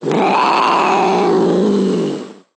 sounds / monsters / cat / c_hit_2.ogg